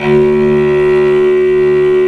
55m-orc01-D1.wav